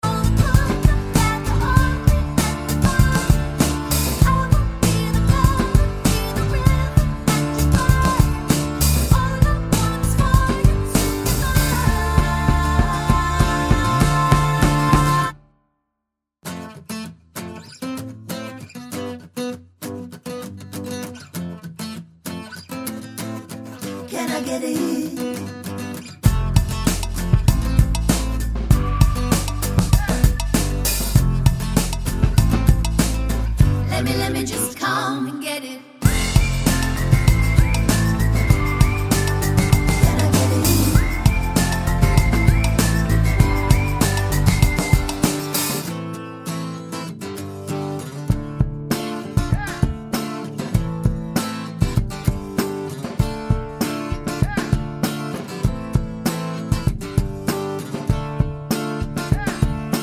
No Low BV on Bridge 2 Pop